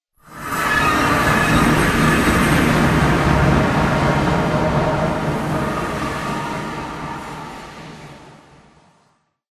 MinecraftConsoles / Minecraft.Client / Windows64Media / Sound / Minecraft / portal / travel.ogg
travel.ogg